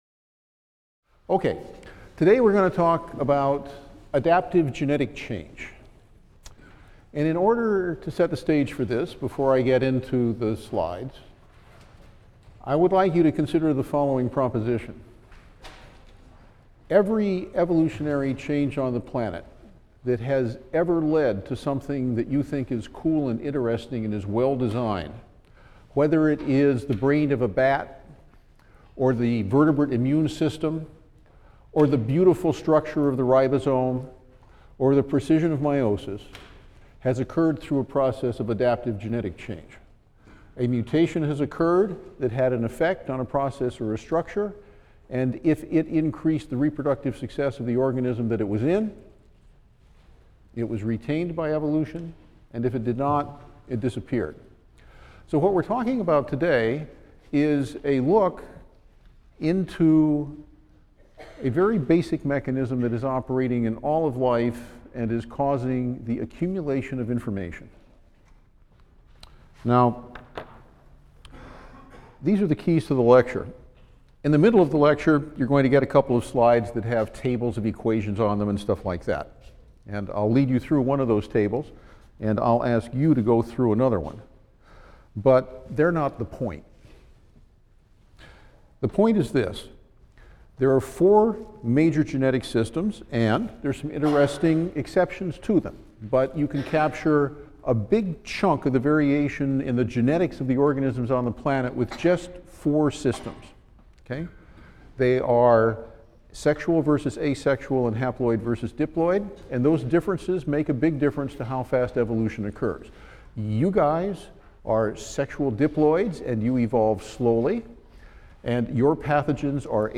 E&EB 122 - Lecture 5 - How Selection Changes the Genetic Composition of Population | Open Yale Courses